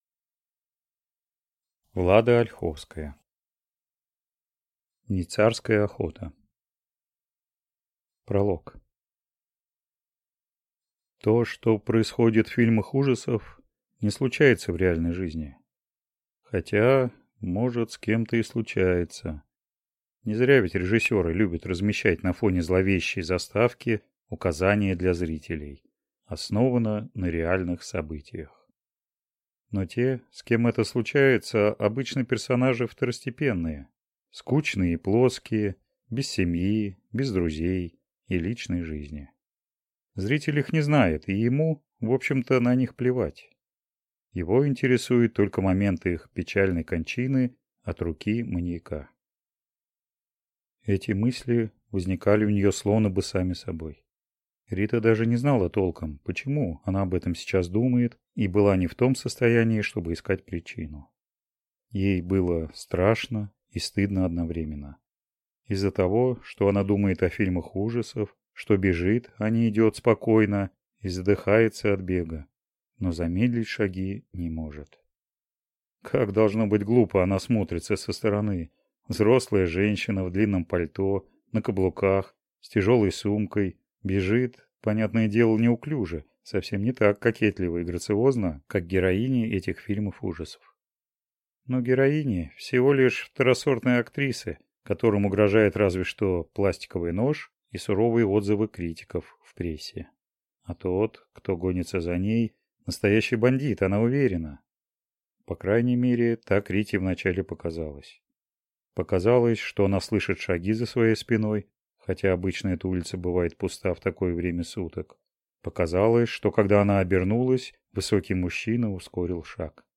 Аудиокнига Сезон псовой охоты | Библиотека аудиокниг